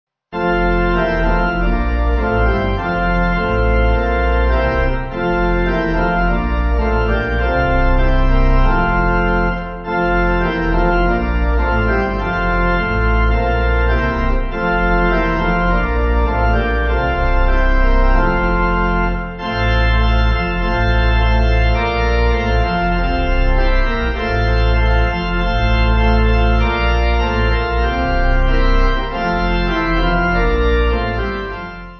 8.7.8.7.D